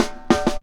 03DR.BREAK.wav